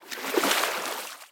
water-12.ogg